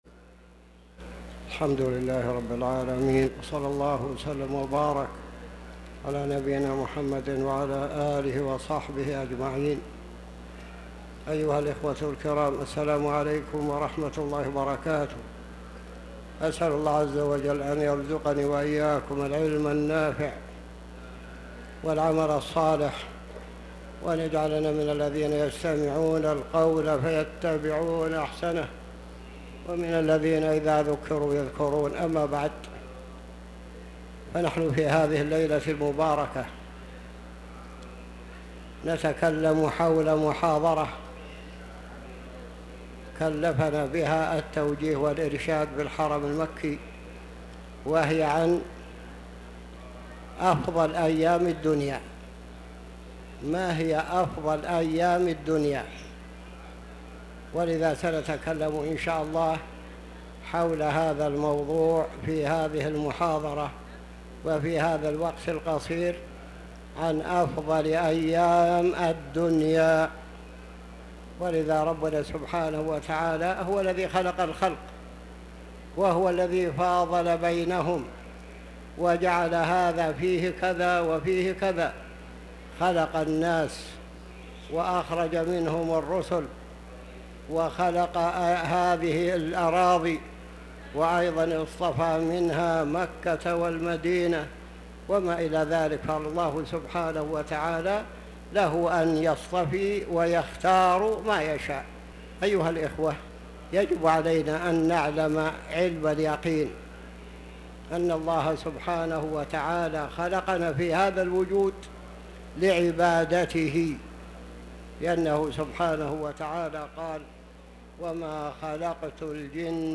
تاريخ النشر ٢٤ ذو القعدة ١٤٤٠ هـ المكان: المسجد الحرام الشيخ